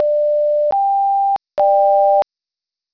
Zunächst werden zwei Töne, die sich um einen Halbton unterscheiden, nacheinander und dann zusammen gespielt.
zwei_toene_1_neu.wav